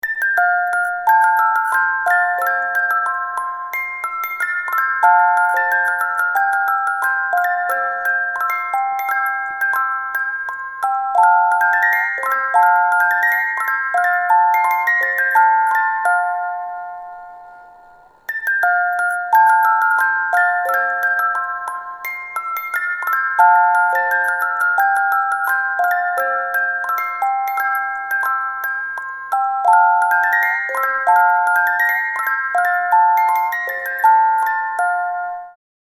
Muziekdoos ‘Vogelhuisje’, met twee kinderen, met 18-delig muzikaal binnenwerk